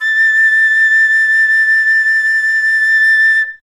51c-flt25-A5.wav